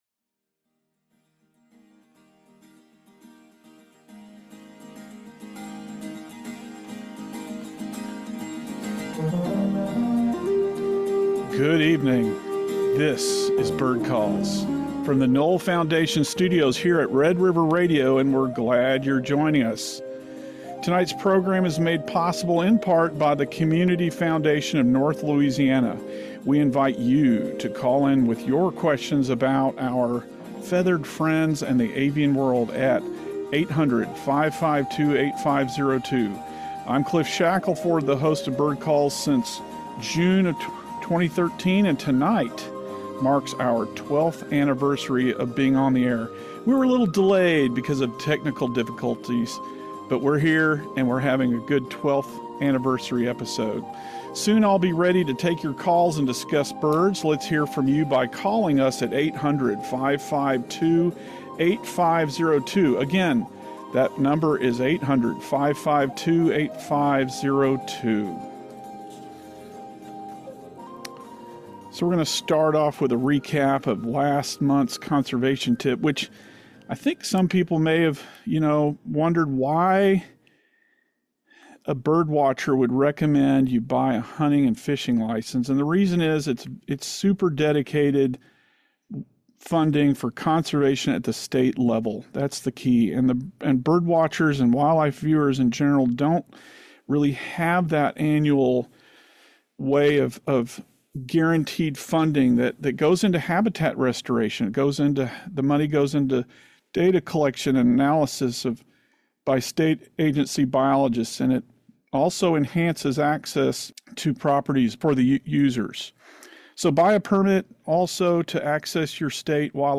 bird-calls-6.3.25.mp3